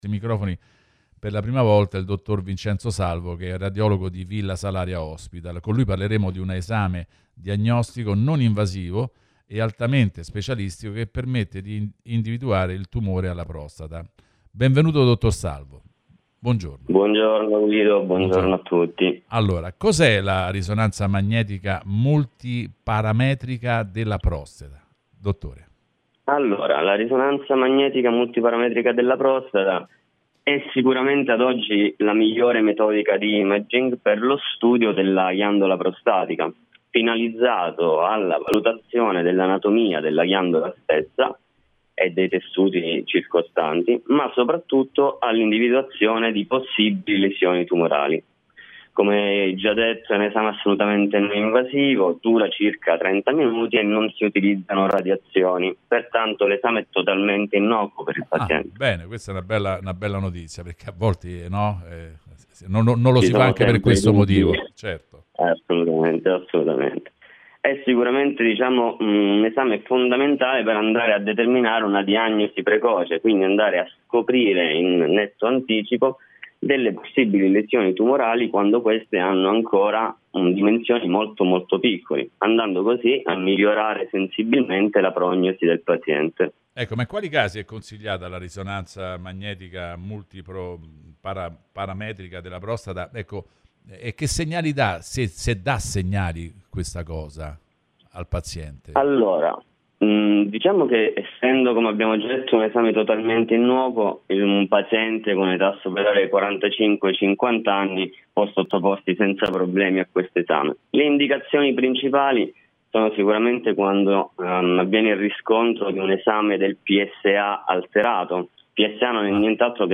Approfondimenti: Intervista al dott.